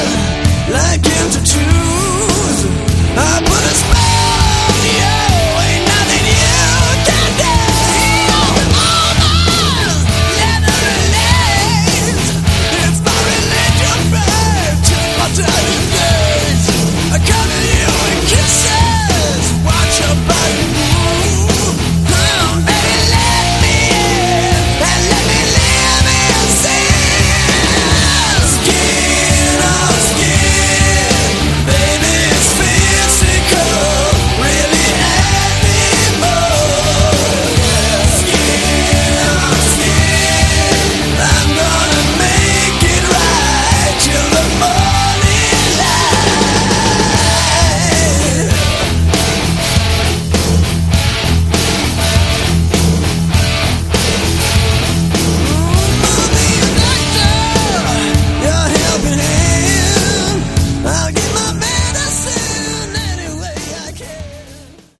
Category: Sleaze/Hard Rock
Guitars
Bass
Drums